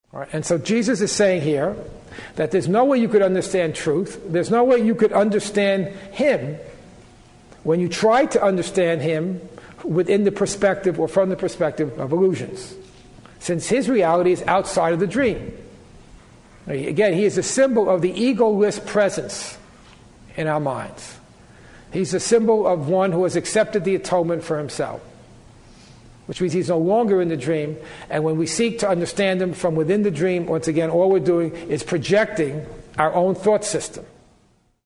This discussion was part of a four-day class held in Atlanta in 2003.